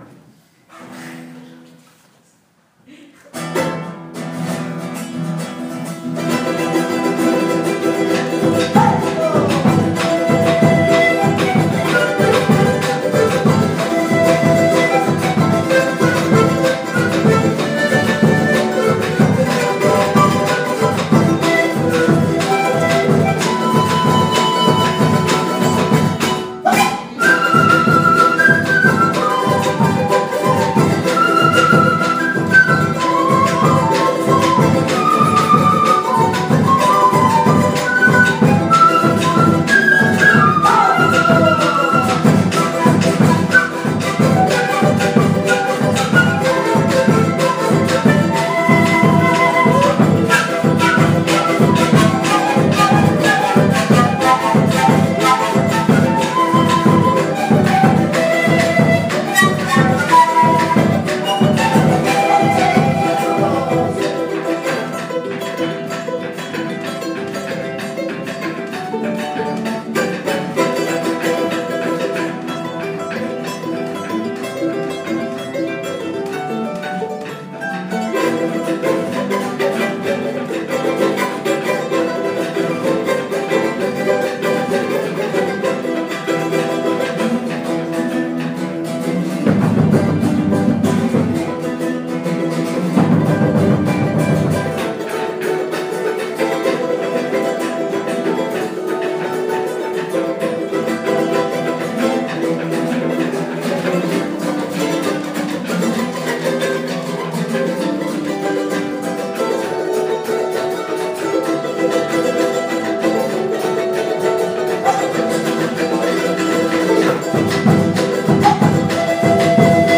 Trotz 4-stündiger Rückfahrt vom Titicacasee, haben wir die Energie aufgebracht, heute Abend im Museo de Instrumental Musicos ein Konzert einer bolivianischen Combo zu besuchen, die klassische bolivianische Musik spielt. 2,5h lang gab es sehr coole und ausnehmend gute handgemachte Musik auf die Ohren.
Im Prinzip 2 Gitarren, Rücken an Rücken verbaut, auf der einen Seite normales 6-saitiges Girarrenset-up und auf der anderen Seite, 8 Saiten ein wenig ähnlich vom Klang wie eine Mandoline. Und mittem im Stück hat er einfach immer mal wieder rumgedreht um den veränderten Klang zu nutzen.
Bolivian-Music-3.m4a